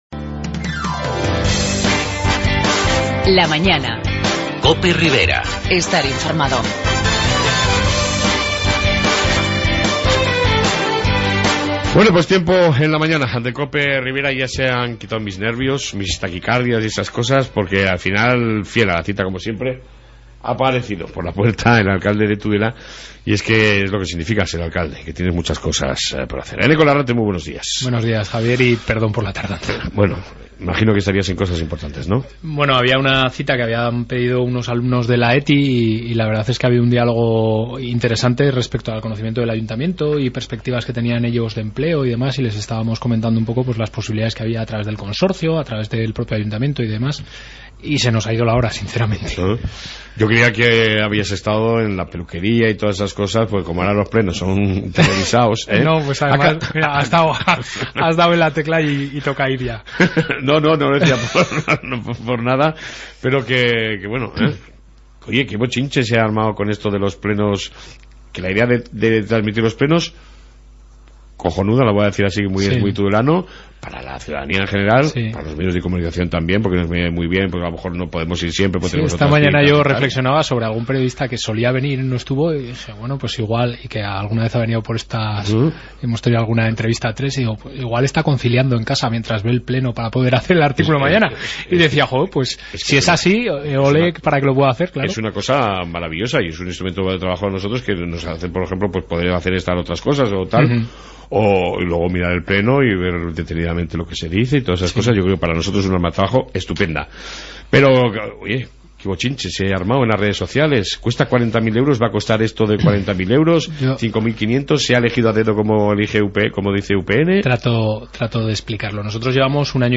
AUDIO: Amplia entrevista con el alcalde de Tudela Eneko Larrarte 31/01/2001